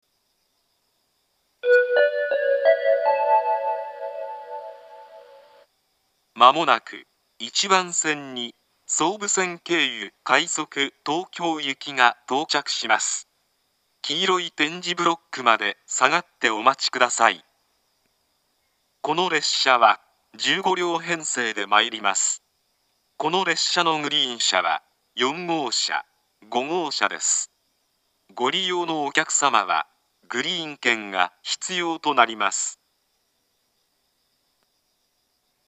１番線接近放送
自動放送は合成音声でした。